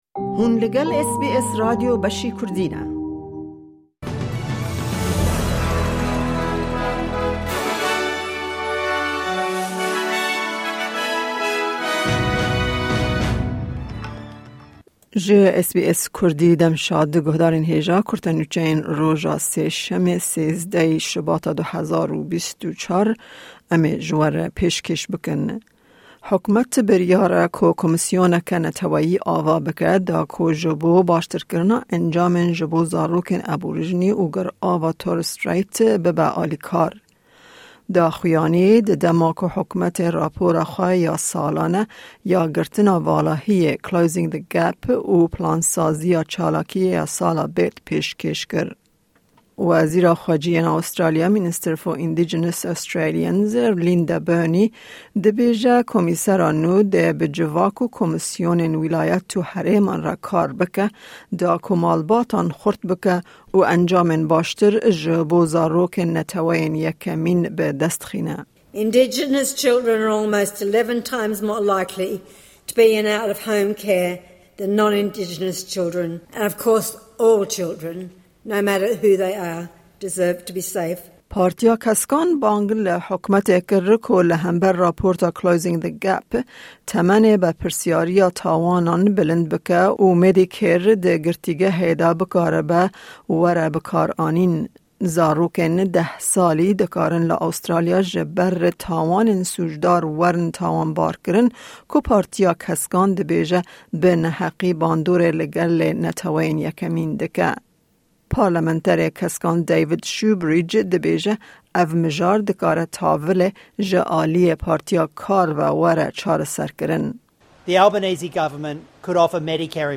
Kurte Nûçeyên roja Sêşemê 13î Şubata 2024